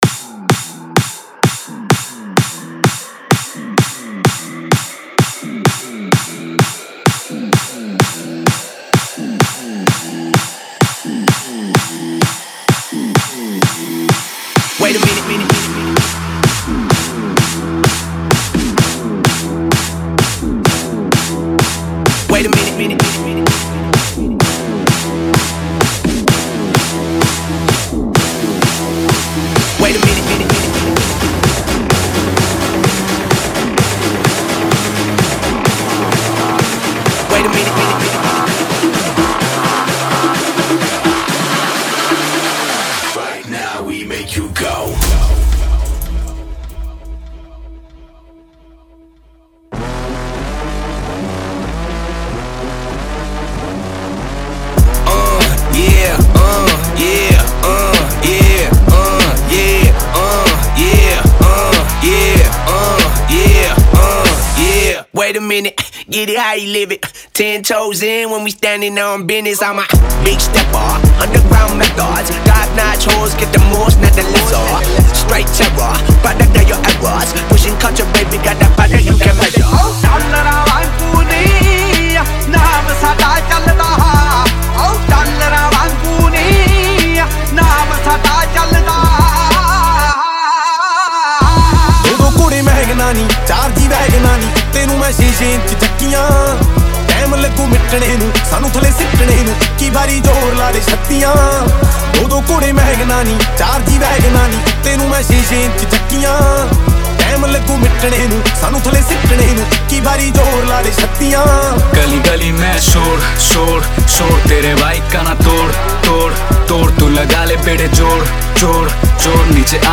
Megamix